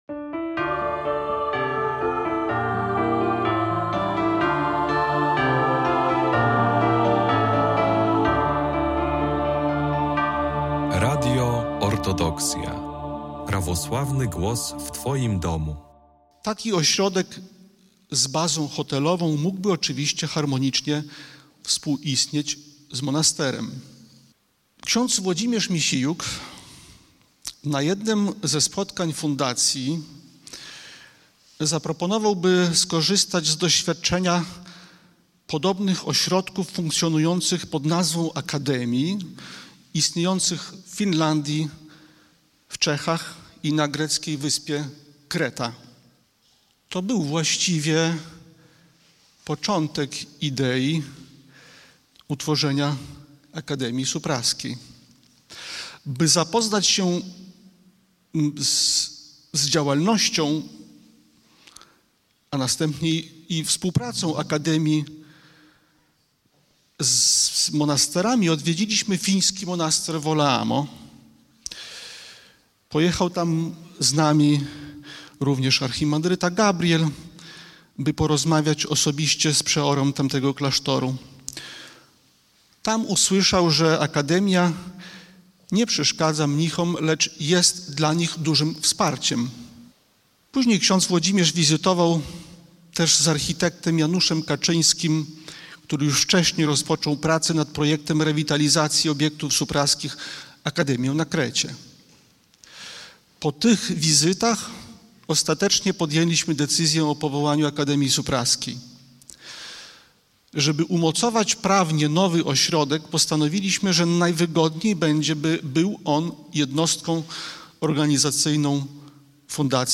Zapraszamy do wysłuchania relacji z obchodów 20-lecia Akademii Supraskiej. Spotkanie w Supraślu było okazją do wspomnień, rozmów i podsumowań dwóch dekad działalności Akademii – miejsca, które łączy edukację, kulturę i dialog między ludźmi z różnych środowisk.